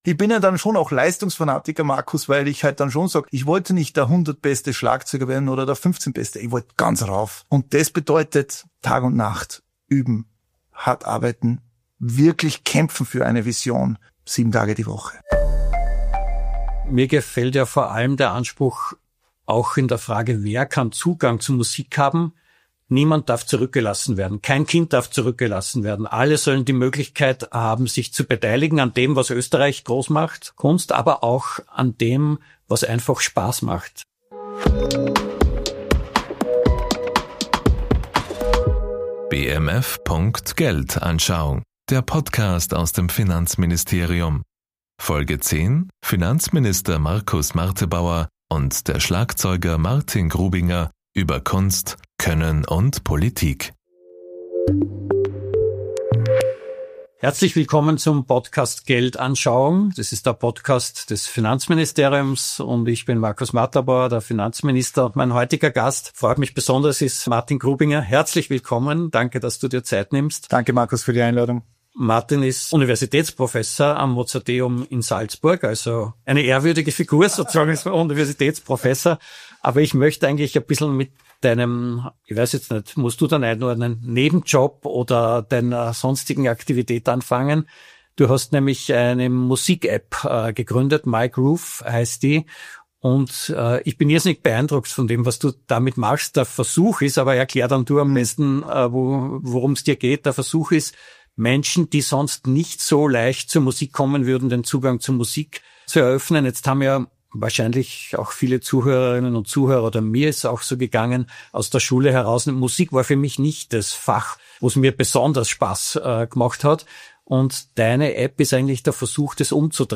#10 Finanzminister Markus Marterbauer und der Schlagzeuger Martin Grubinger über Kunst, Können und Politik ~ BMF.Geldanschauung Podcast
Wie kommt man zu Spitzenleistungen? Wie soll unsere Gesellschaft aussehen? Ein Gespräch über Begeisterungsfähigkeit, Leistungswillen, die Kraft der Musik, ein starkes Europa und Gemeinschaftssinn.